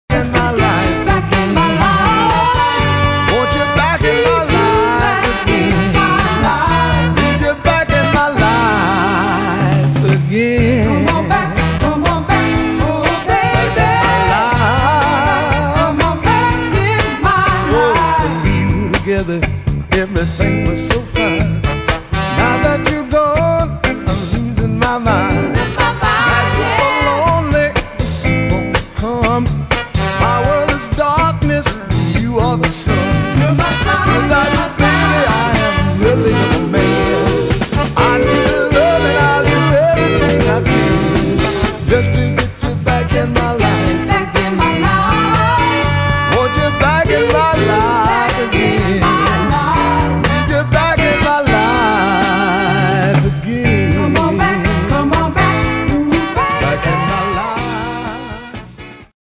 [ FUNK / SOUL ]